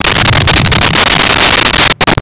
Sfx Whoosh 5103
sfx_whoosh_5103.wav